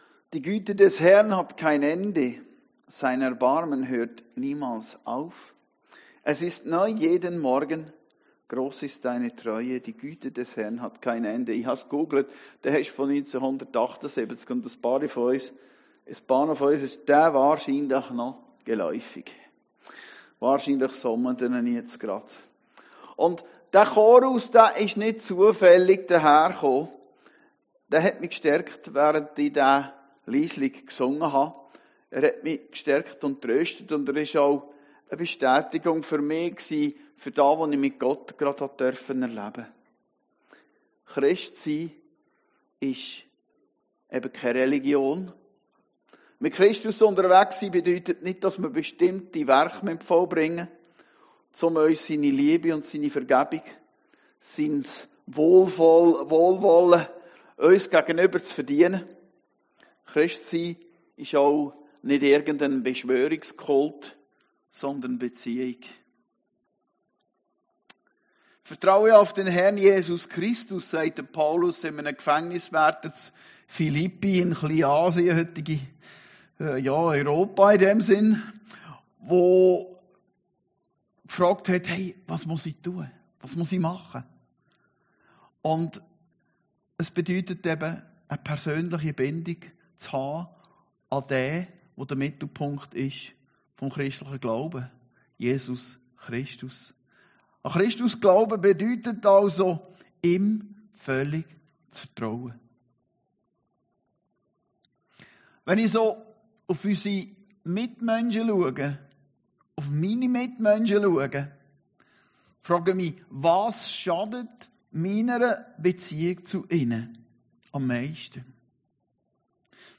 Predigten Heilsarmee Aargau Süd – Gottes Gnade (er)leben in einer verkehrten Welt